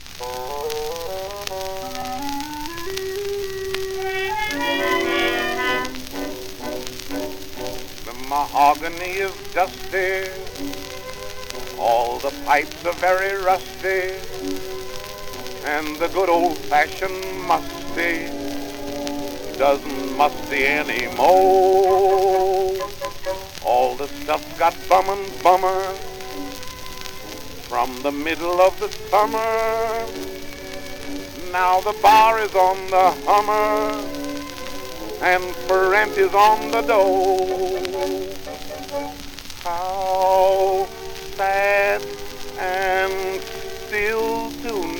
Jazz, Stage & Screen, Ragtime　USA　12inchレコード　33rpm　Mono